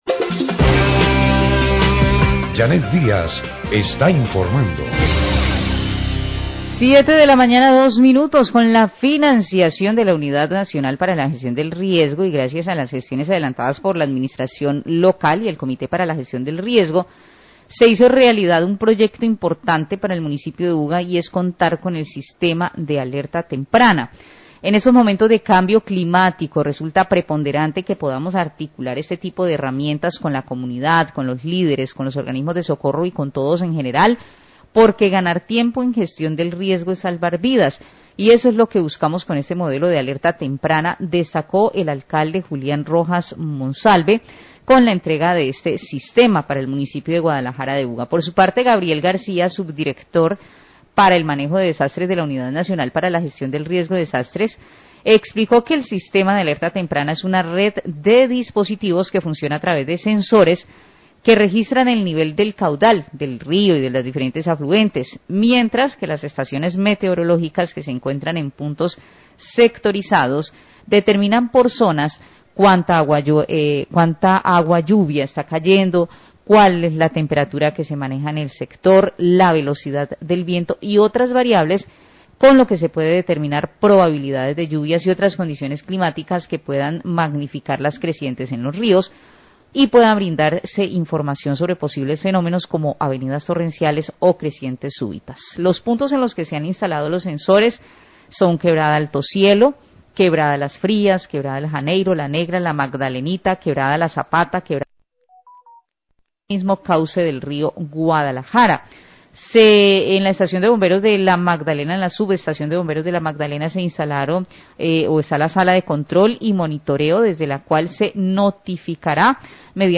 Periodista explica cómo funciona el sistema de alertas tempranas del Río Guadalajara
Radio